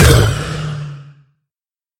sounds / mob / wither / hurt2.mp3
hurt2.mp3